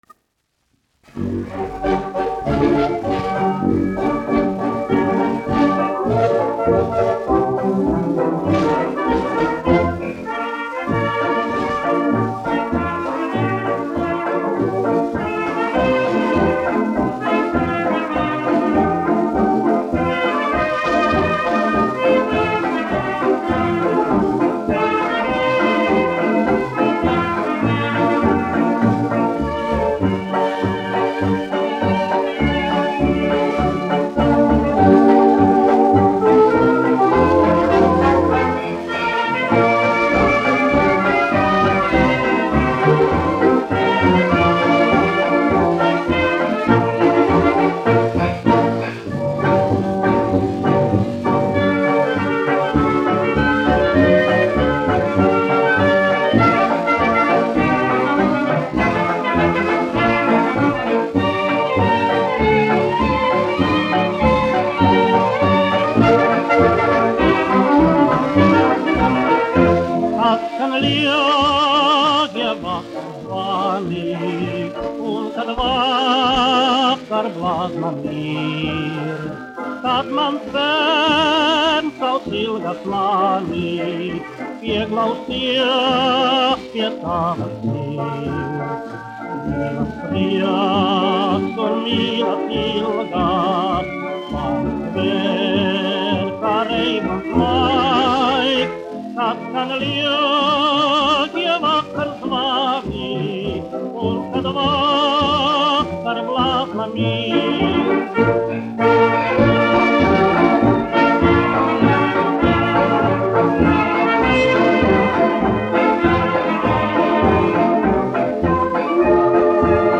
1 skpl. : analogs, 78 apgr/min, mono ; 25 cm
Populārā mūzika
Fokstroti
Skaņuplate